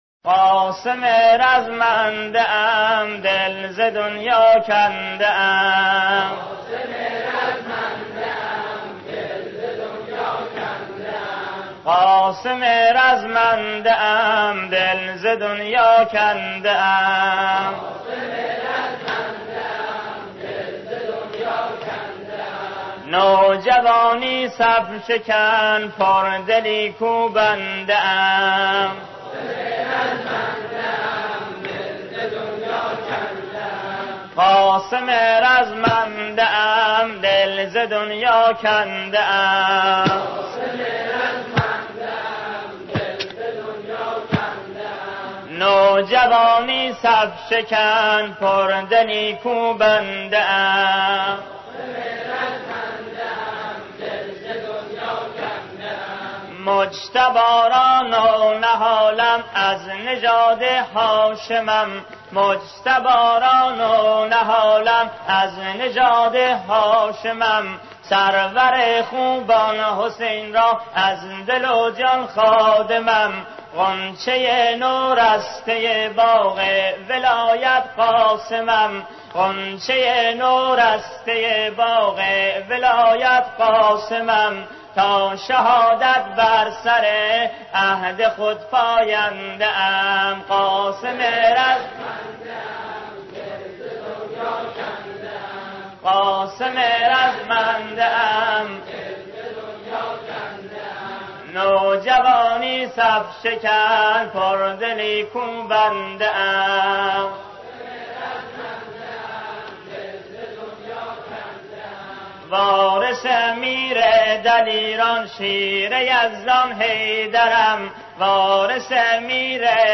شوخی رزمندگان کرمانی با حاج‌ «قاسم سلیمانی» به سبک مداحی آهنگران+ صوت
حاج «صادق آهنگران» به روایت یکی از خاطرات خود در جمع رزمندگان لشکر ۴۱ ثارالله (ع) در دفاع مقدس پرداخت.